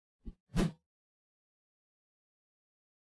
meleeattack-swoosh-heavy-group03-01.ogg